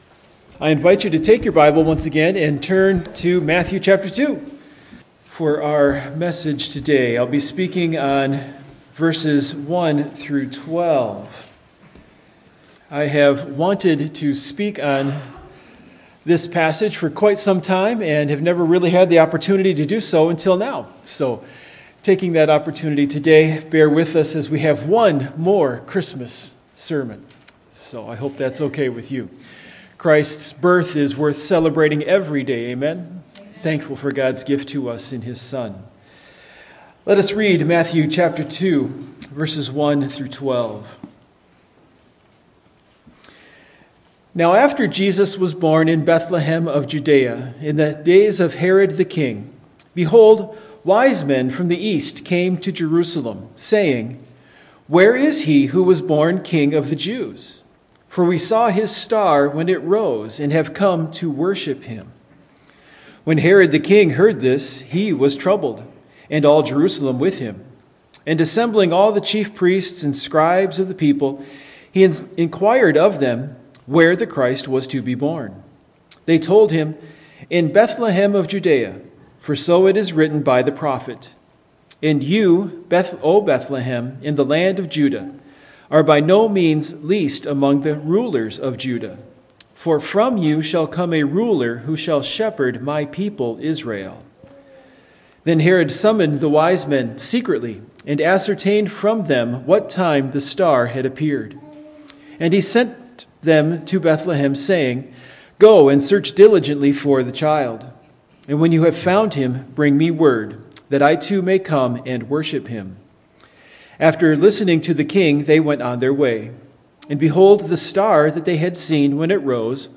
Christmas Passage: Matthew 2:1-12 Service Type: Morning Worship Topics